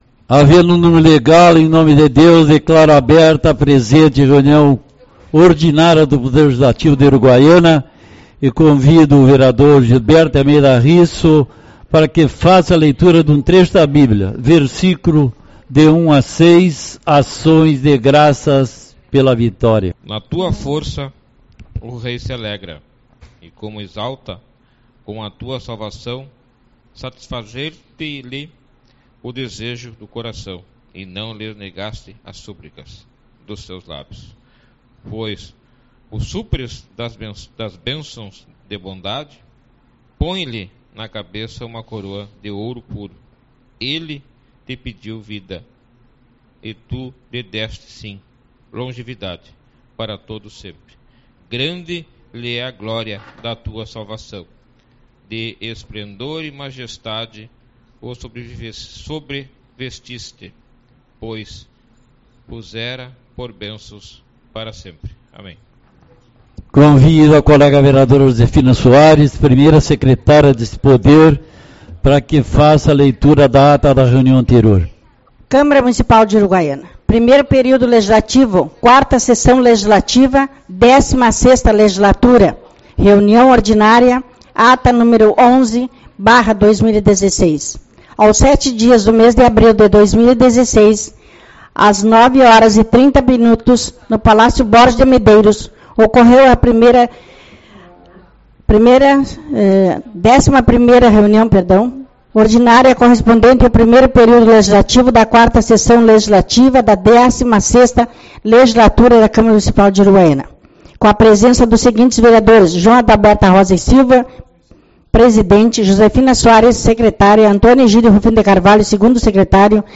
12/04 - Reunião Ordinária